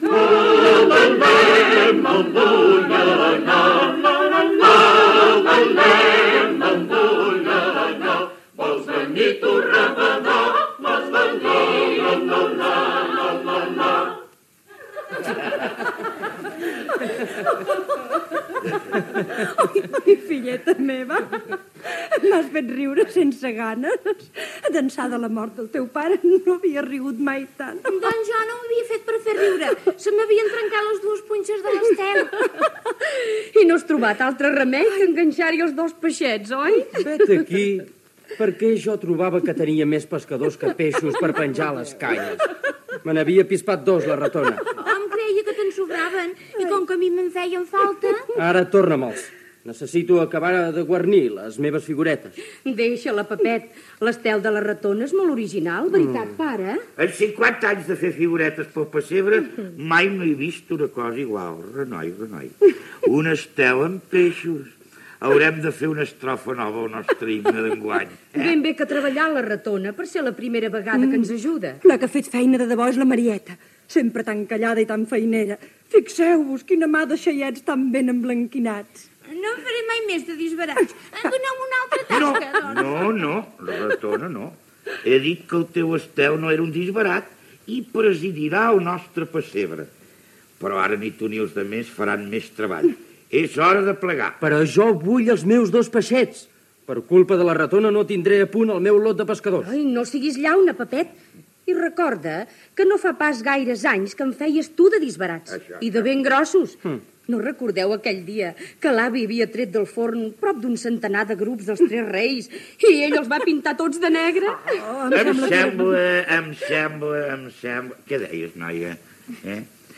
La família es prepara per a la nit de Nadal i canten dues nadales, una dedicada a les figuretes del passebre i l'altra "El noi de la mare".
Gènere radiofònic Ficció Data emissió 1963-12-25 Banda OM Localitat Barcelona Comarca Barcelonès Durada enregistrament 11:31 Idioma Català Notes Direcció